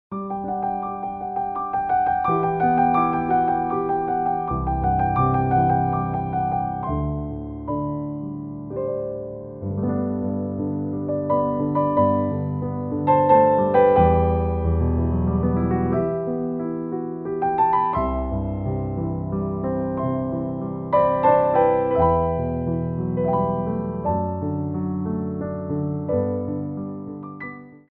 3/4 (16x8)